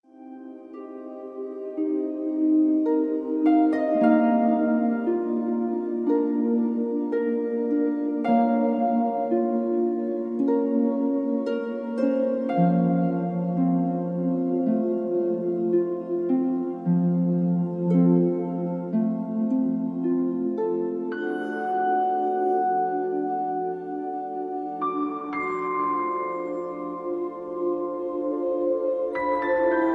Hier ist die Musik OHNE Sprache.